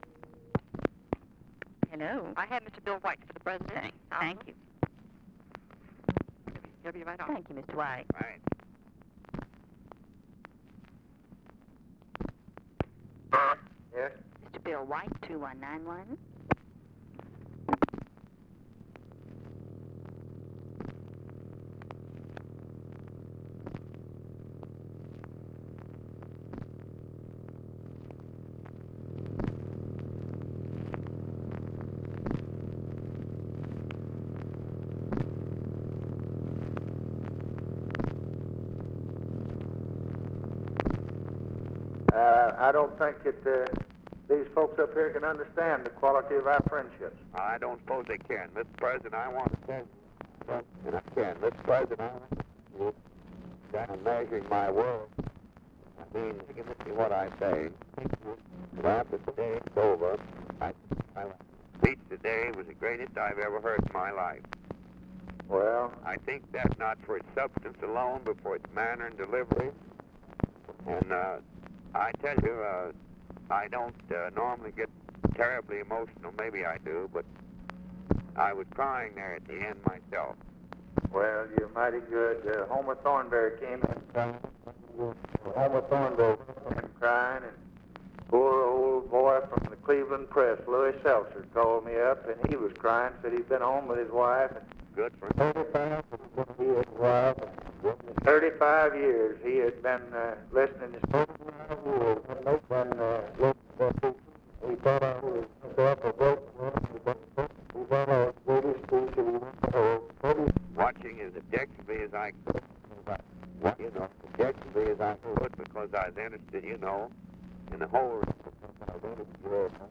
Conversation with WILLIAM S. WHITE, November 27, 1963
Secret White House Tapes